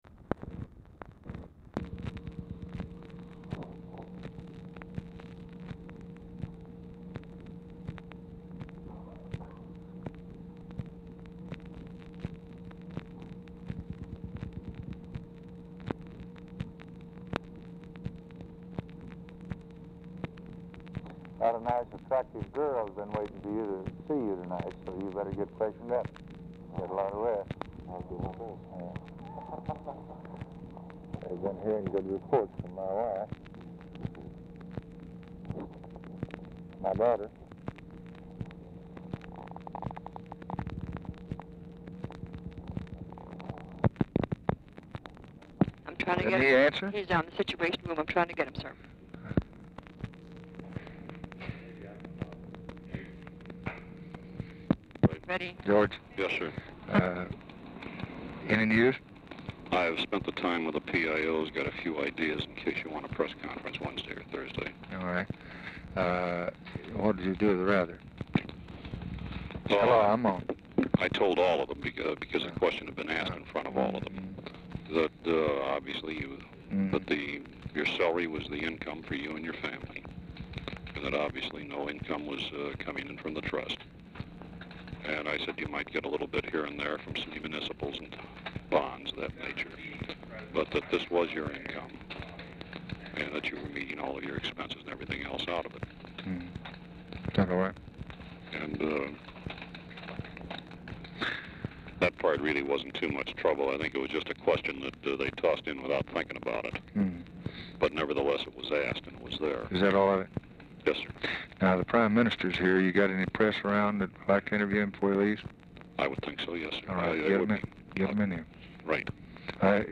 Telephone conversation
OFFICE CONVERSATION PRECEDES CALL
Format Dictation belt